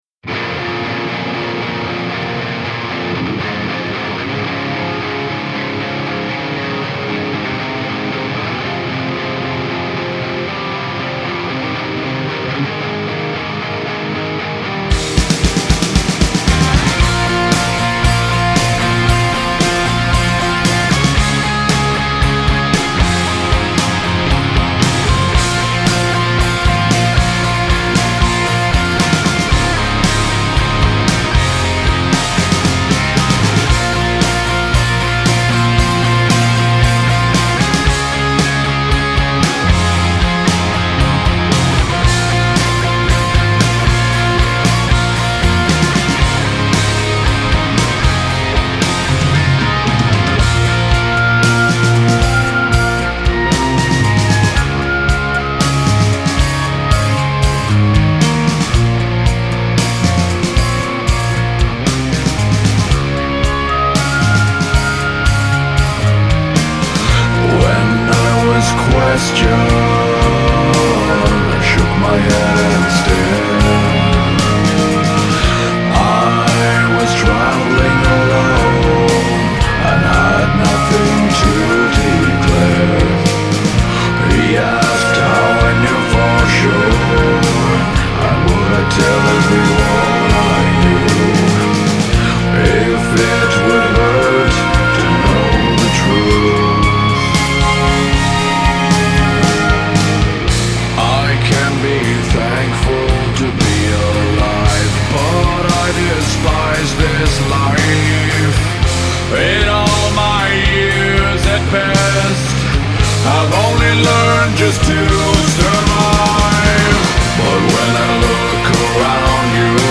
black and doom metal band